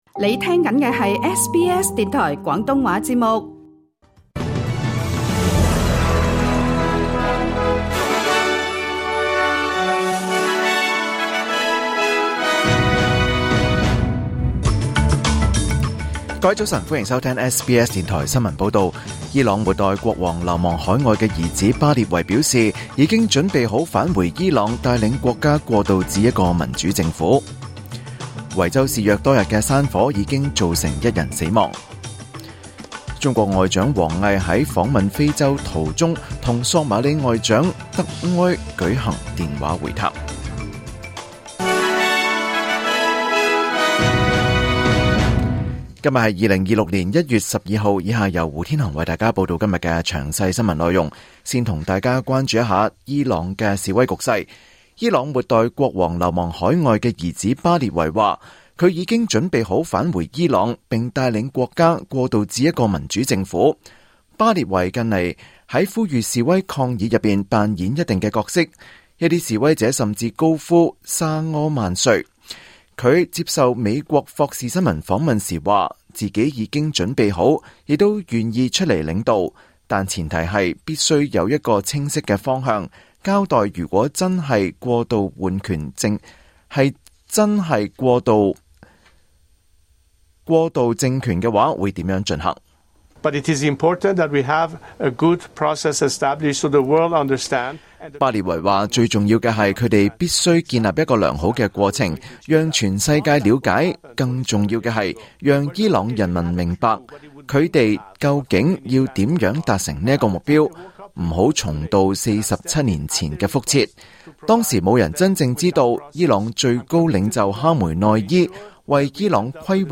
2026年1月12日SBS廣東話節目九點半新聞報道。